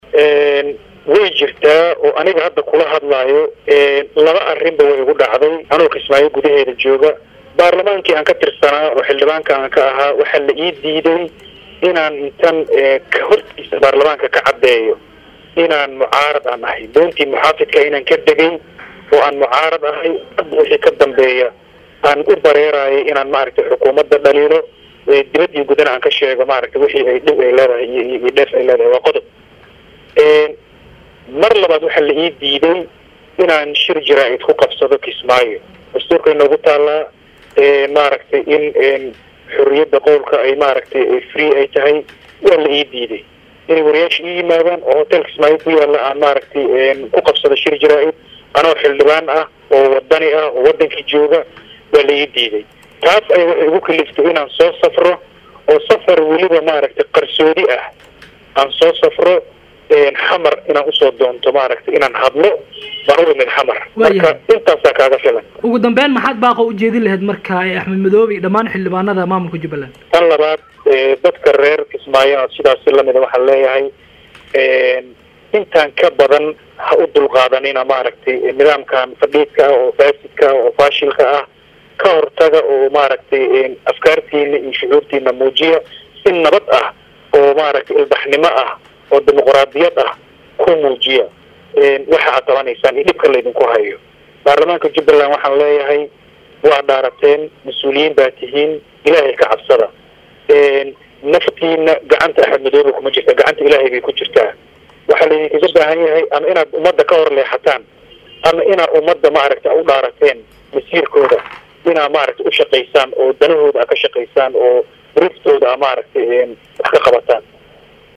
Xildhibaan Maxamed Cali Yuusuf oo kamid ah xildhibaanada maamulka Jubaland oo la hadlay saxaafada  magaalada Muqdisho ayaa waxa uu sheegay in madaxweynaha maamulkaasi uu yahay kaligiis talis.